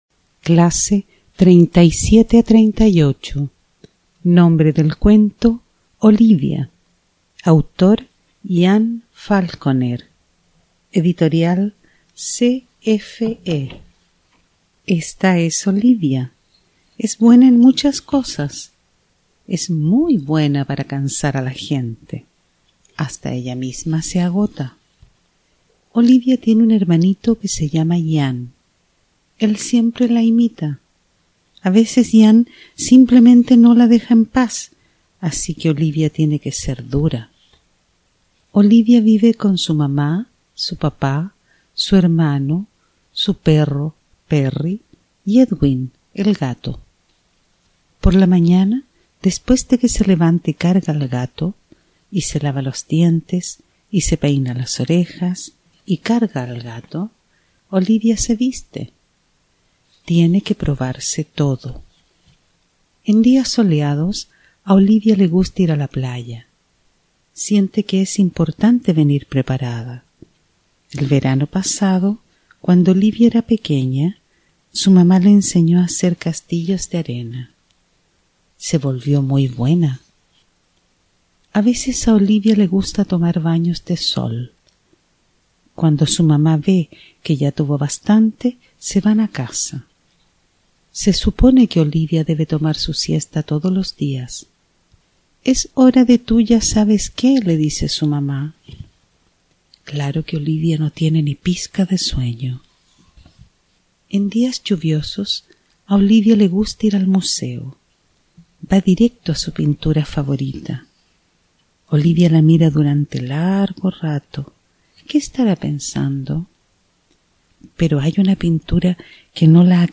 Audiolibro: Olivia
Tipo: Audiolibros